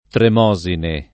[ trem 0@ ine ]